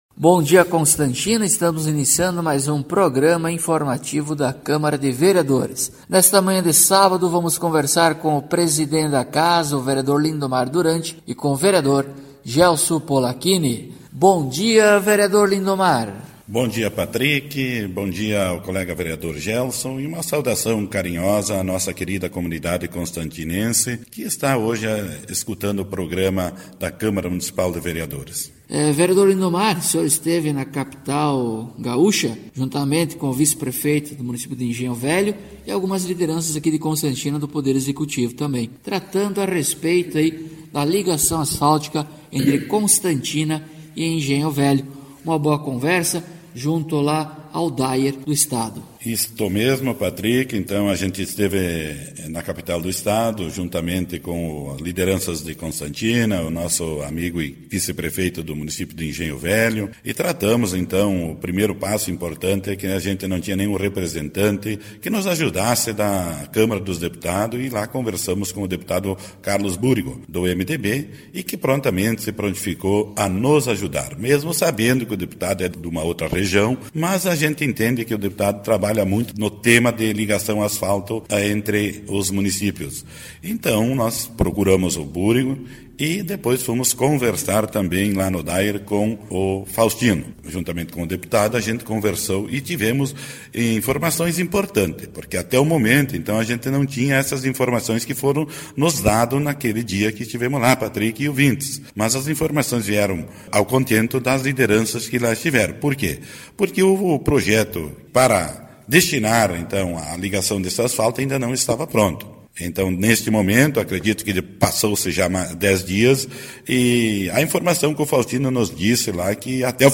Acompanhe o programa informativo da câmara de vereadores de Constantina com o Vereador Lindomar Duranti e o Vereador Gelso Polaquini.